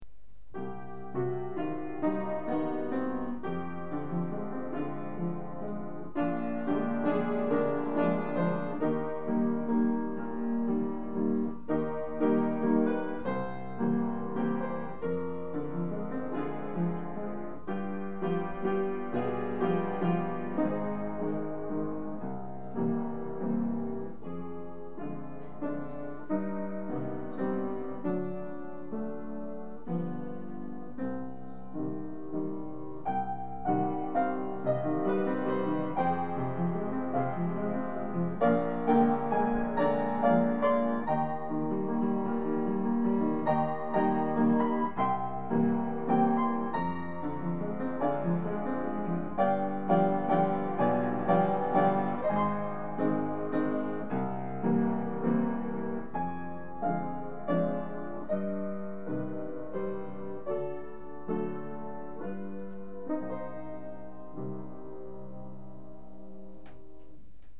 на пианино романс «Дремлют плакучие ивы